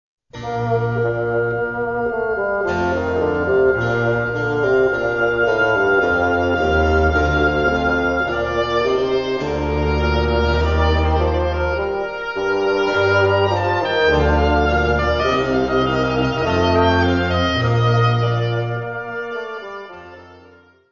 : stereo; 12 cm
Área:  Música Clássica